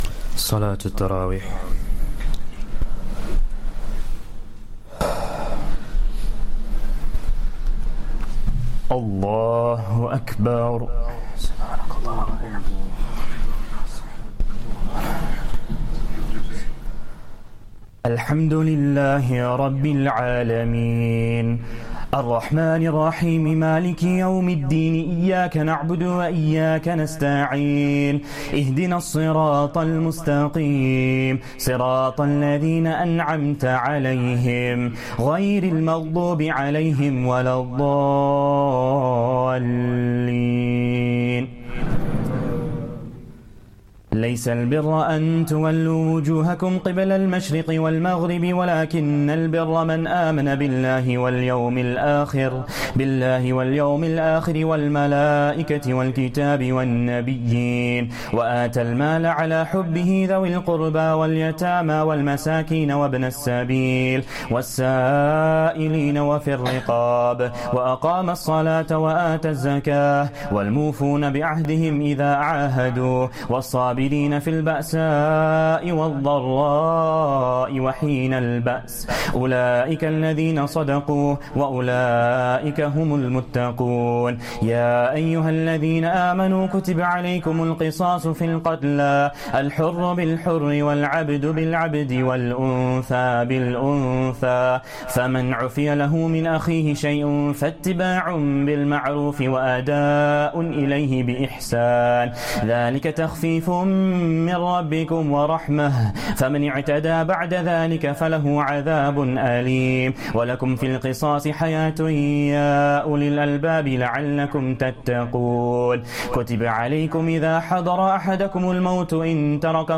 Taraweeh Prayer 2nd Ramadan